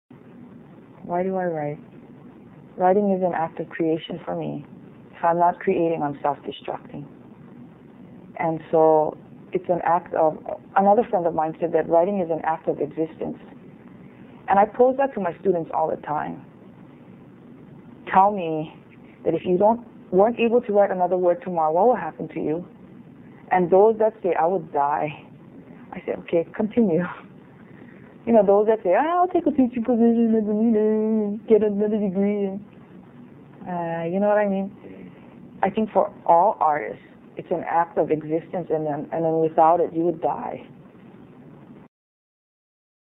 Q&A with Lois-Ann Yamanaka